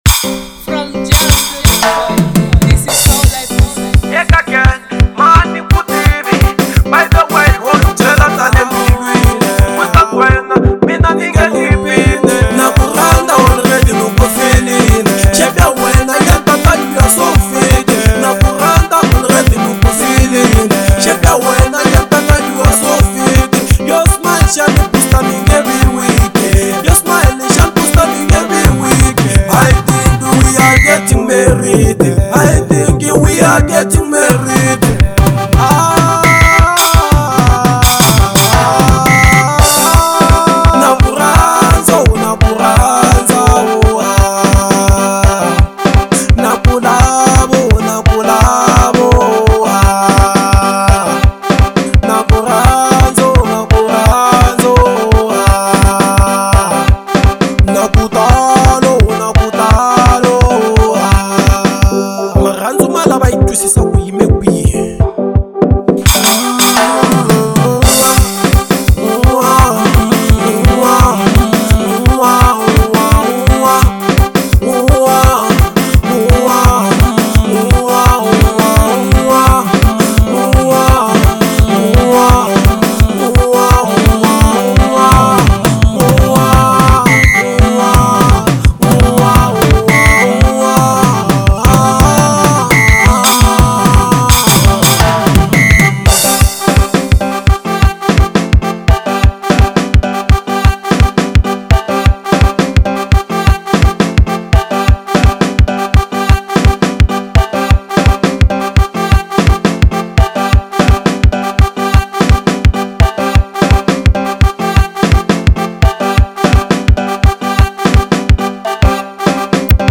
03:02 Genre : Xitsonga Size